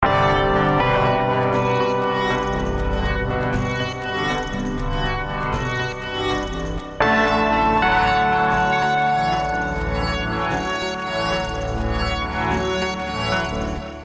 标签： 120 bpm Fusion Loops Synth Loops 2.36 MB wav Key : Unknown
声道立体声